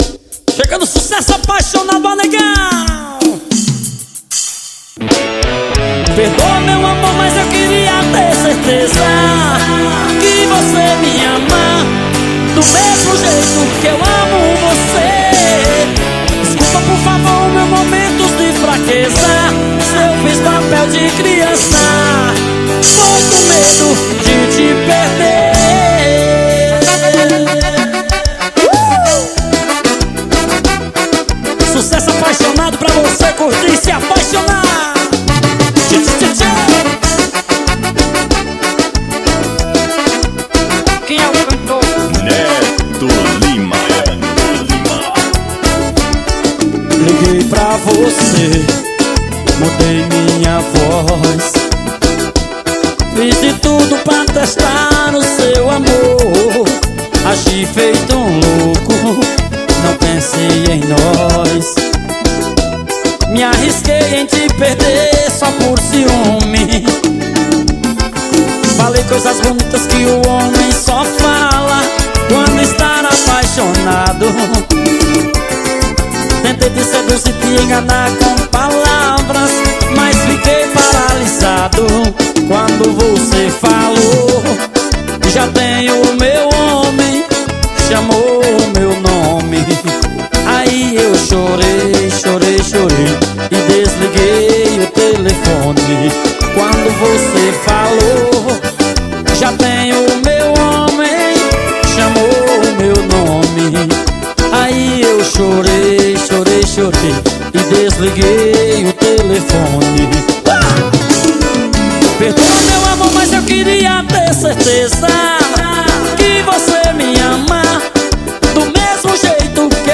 forro.